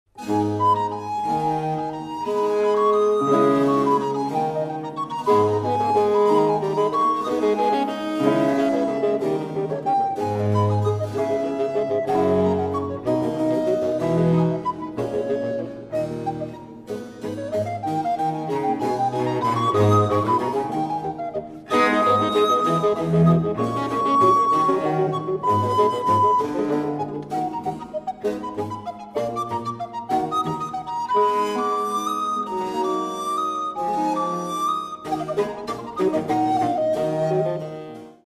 • Качество: 128, Stereo
красивые
спокойные
без слов
красивая мелодия
инструментальные
оркестр
классика
классическая музыка